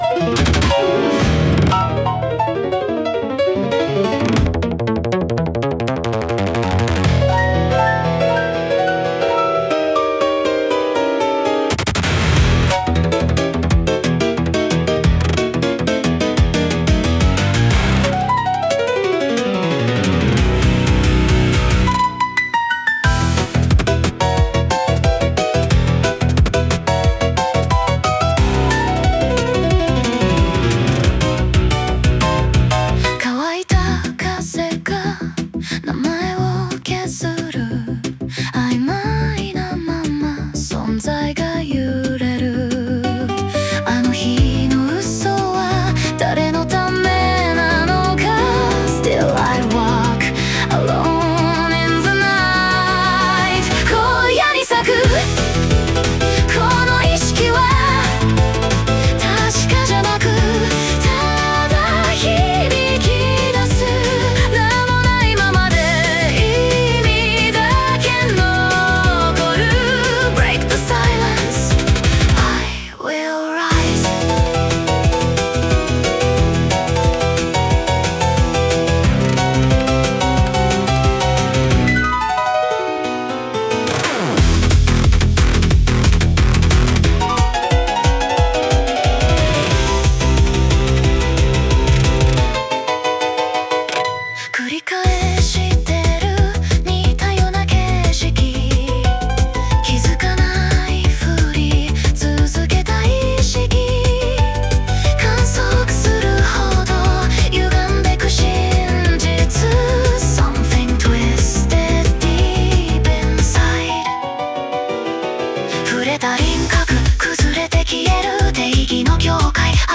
女性ボーカル
イメージ：ピアノ,エクスペリメンタル・ミュージック,グリッチ,アヴァンギャルド,女性ボーカル